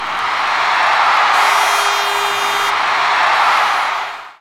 5407L CROWD.wav